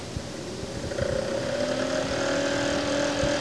fan1.wav